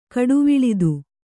♪ kaḍuviḷidu